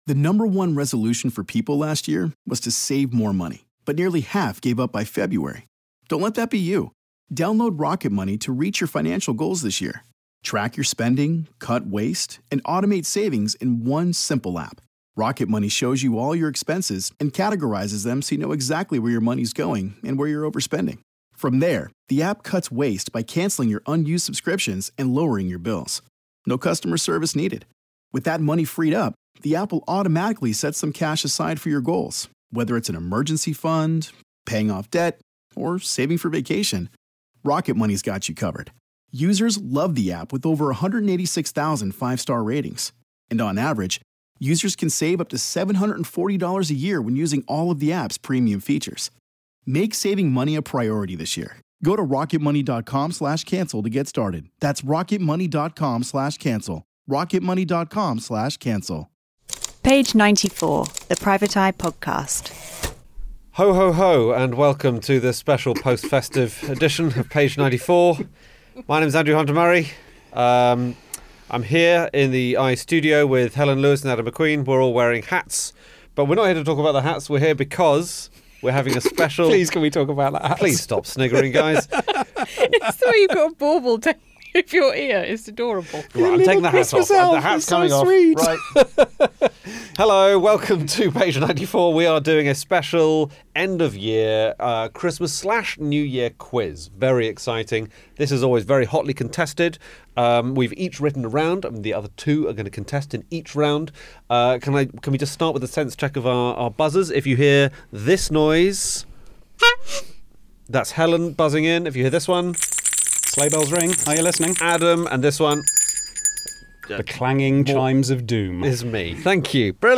It's the Eye Quiz of the last year!